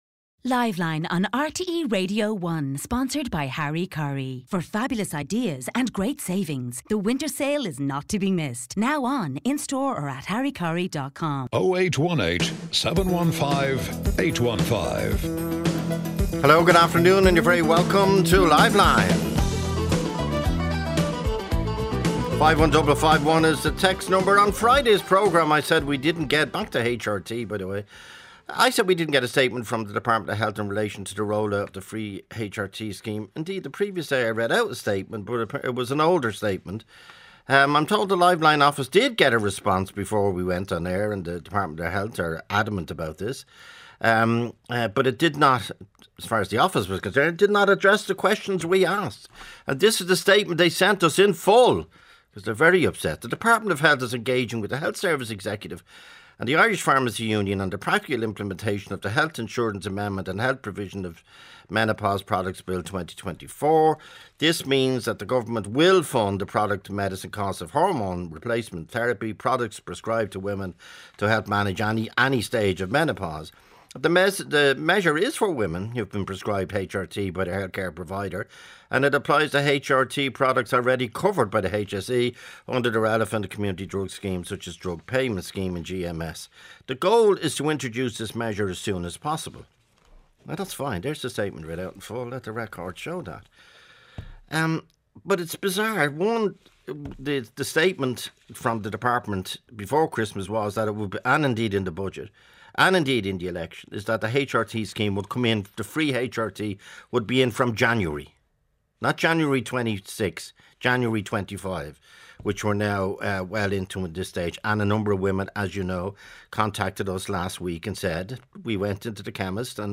Joe Duffy talks to the Irish public about affairs of the day.